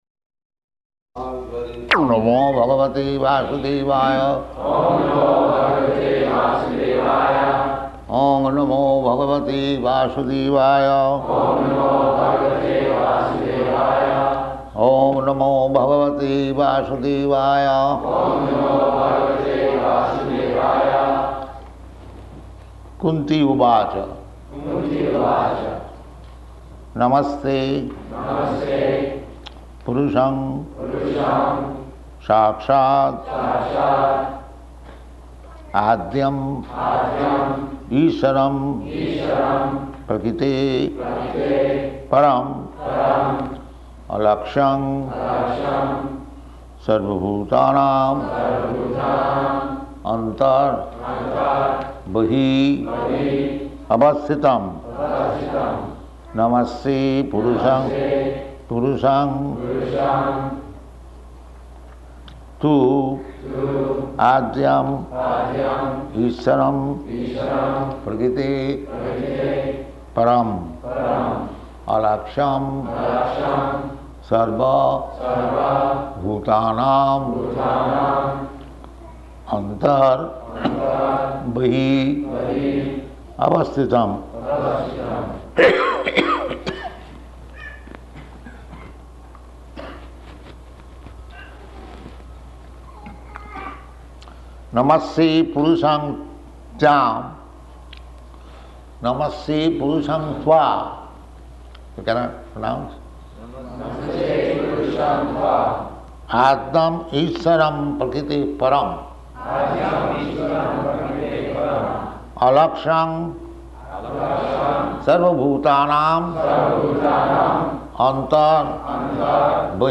Śrīmad-Bhāgavatam 1.8.18 --:-- --:-- Type: Srimad-Bhagavatam Dated: July 4th 1974 Location: Chicago Audio file: 740704SB.CHI.mp3 Prabhupāda: Oṁ namo bhagavate vāsudevāya.
[devotees repeat] [leads chanting of verse] kunty uvāca namasye puruṣaṁ tu ādyam īśvaraṁ prakṛteḥ param alakṣyaṁ sarva-bhūtānām antar bahir avasthitam [ SB 1.8.18 ] [02:05] This is a prayer offered by Kuntī to Kṛṣṇa.
[children making noises] Devotee: Take the children out.